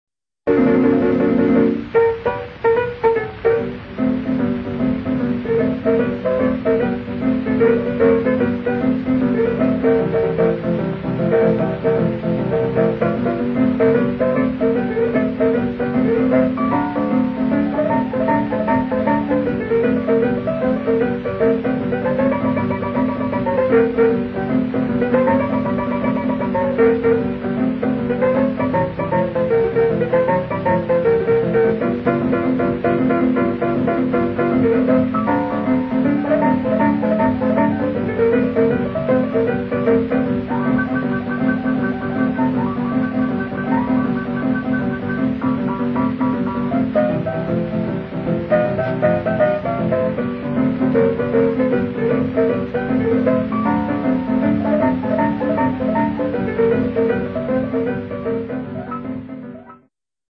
boogie-woogie